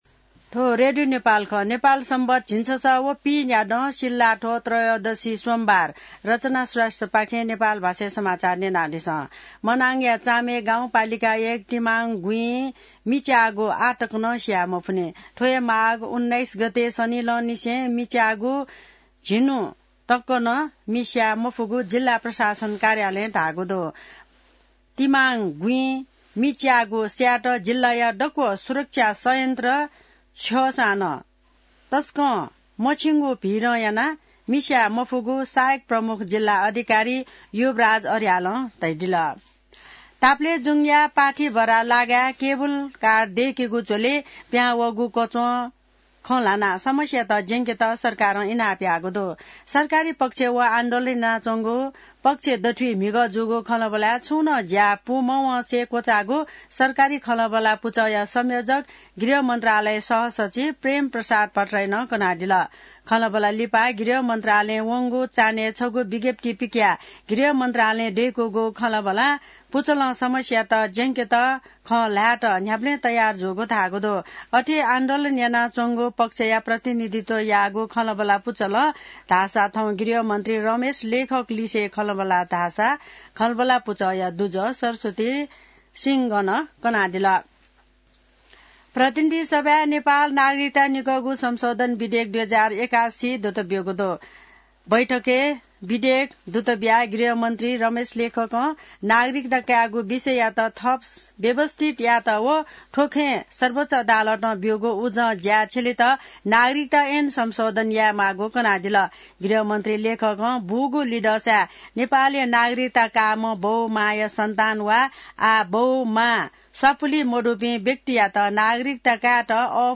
नेपाल भाषामा समाचार : २९ माघ , २०८१